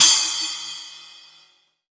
Crashes & Cymbals
Splsh_Cy.wav